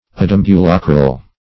Search Result for " adambulacral" : The Collaborative International Dictionary of English v.0.48: Adambulacral \Ad`am*bu*la"cral\, a. [L. ad + E. ambulacral.]